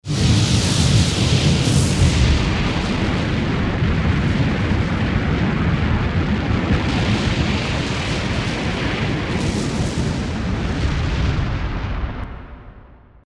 Root > sounds > weapons > hero > skywrath
mystic_flare_scepter.mp3